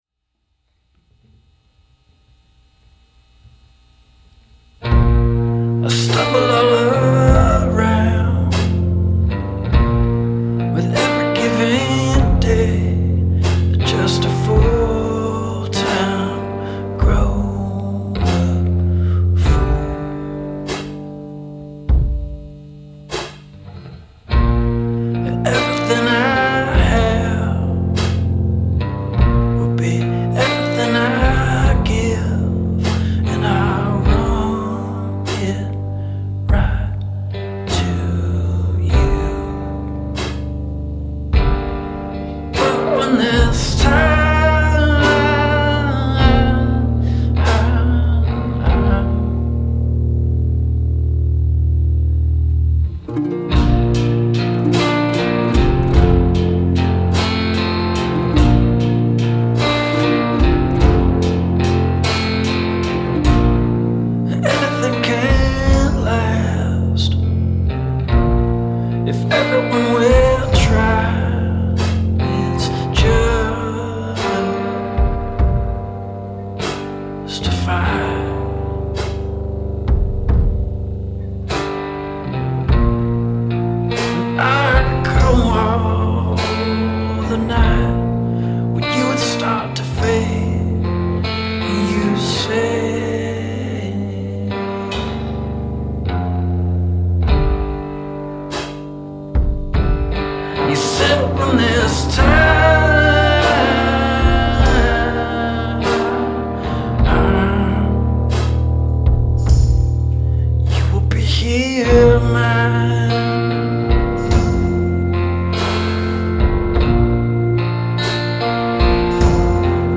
The whole song is a weary unfolding of fits and starts.